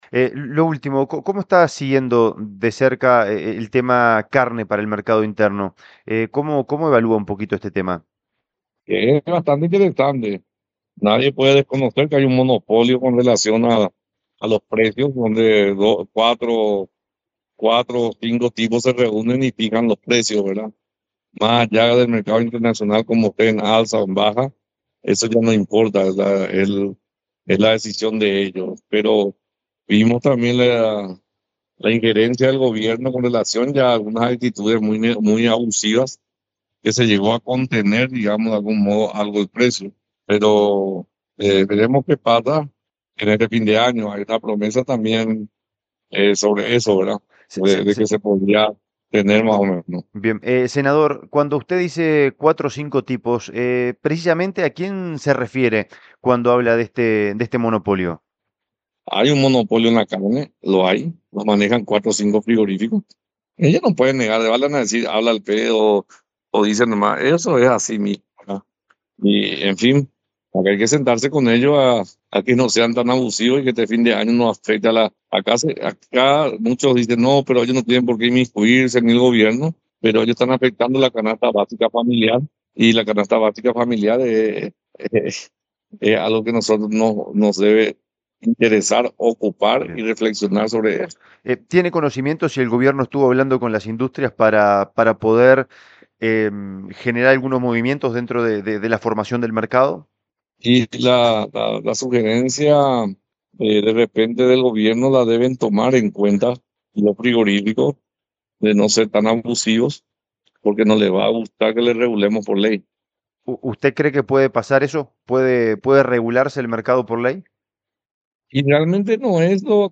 En diálogo con Valor Agregado por Radio Asunción 1250 AM, el parlamentario sostuvo que existe un “monopolio” ejercido por un grupo reducido de frigoríficos que, a su criterio, fijan los valores sin correlación con el movimiento internacional.